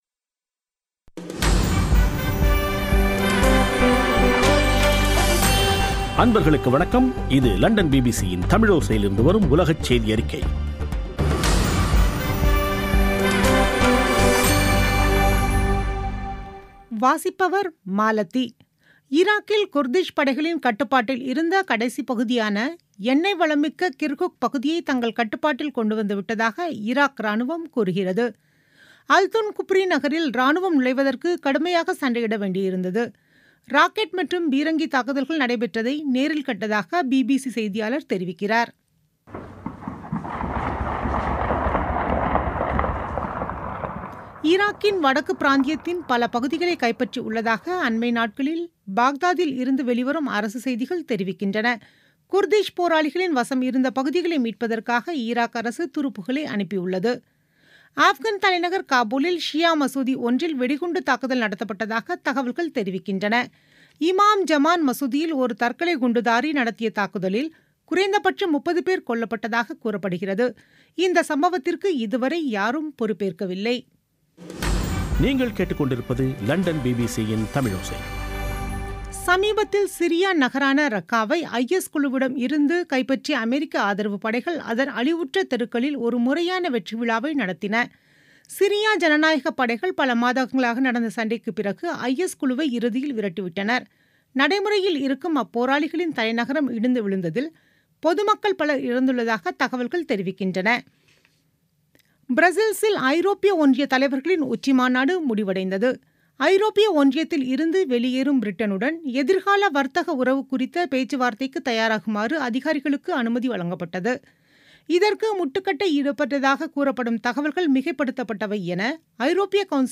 பிபிசி தமிழோசை செய்தியறிக்கை (20.10.2017)